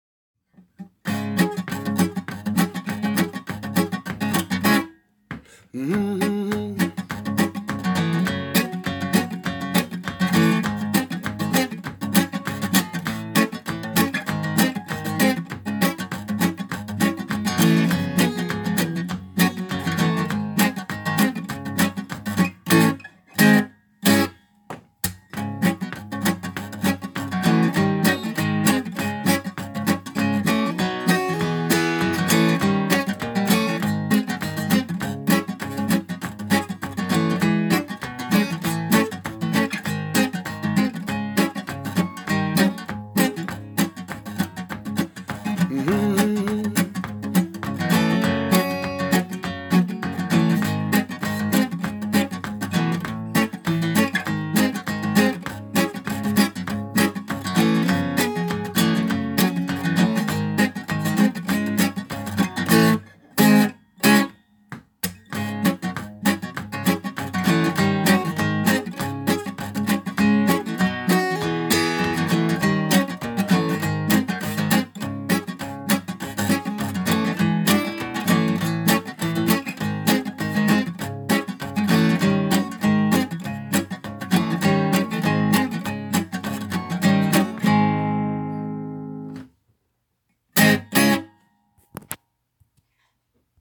(en do# mineur)